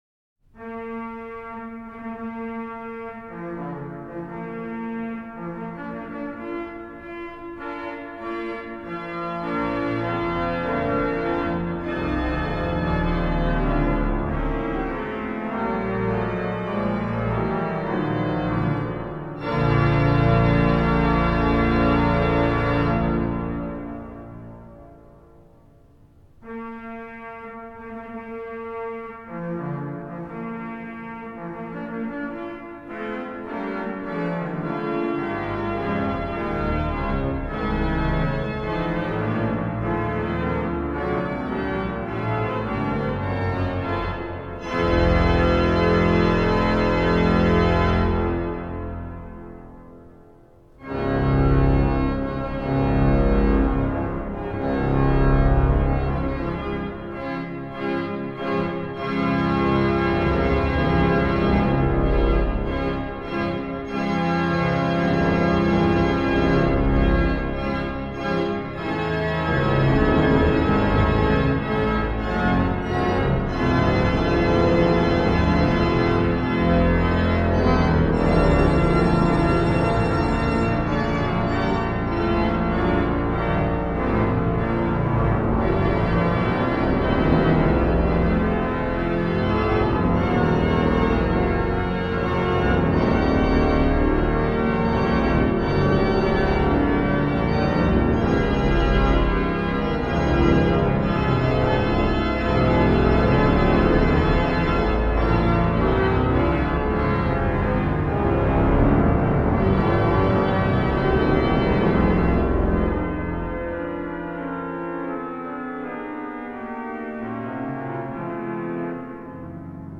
Johann Gustav Eduard Stehle: Saul, a Symphonic Tone Poem
Saul, ein symphonisches Tongemälde